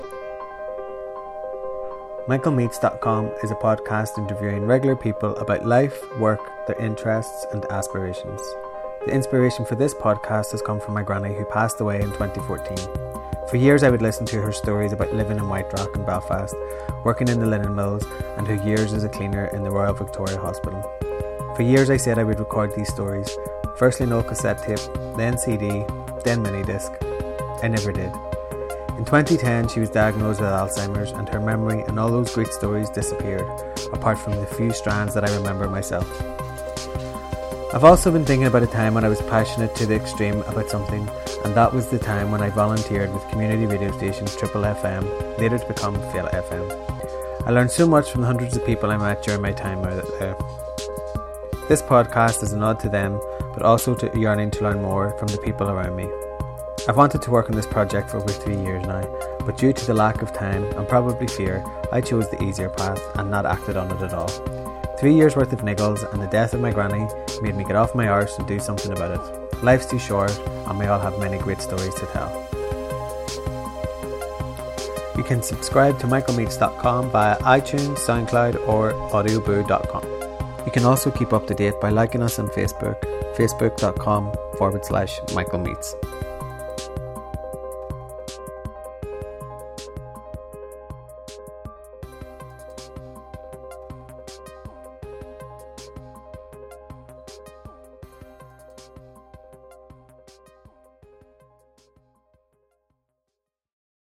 oral history; ireland; northern ireland; spoken word